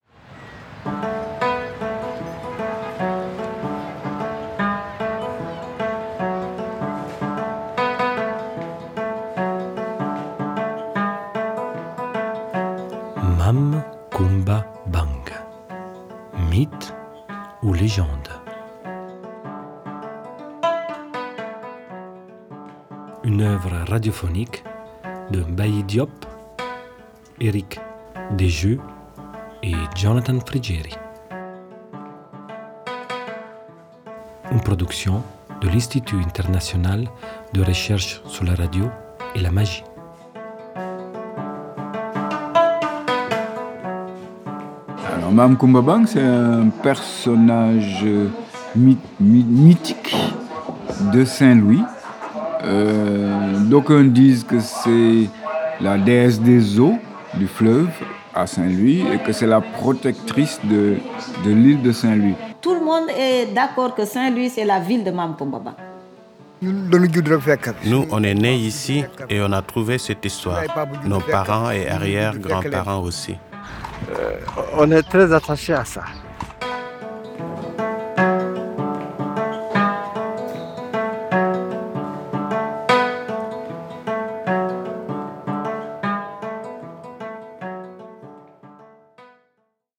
Documentaire radiophonique